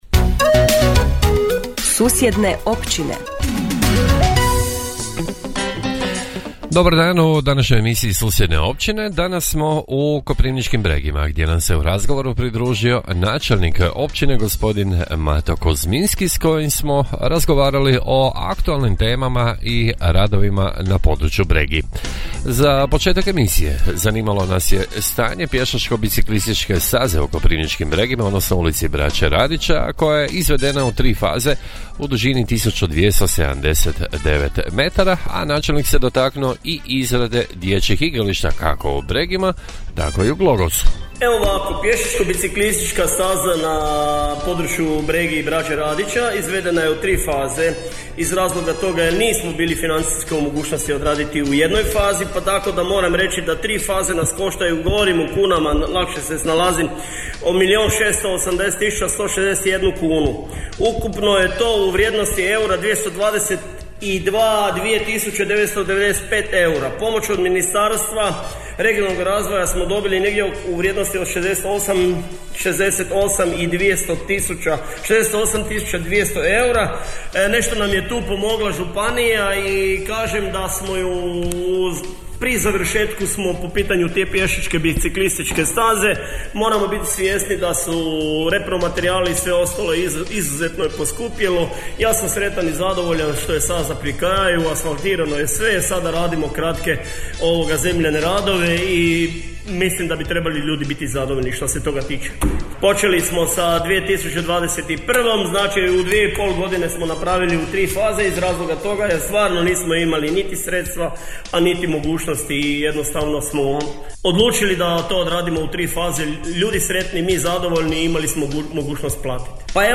U emisiji Susjedne općine gost je bio načelnik općine Koprivnički Bregi Mato Kuzminski koji je govorio o aktualnim temama i radovima na području Bregi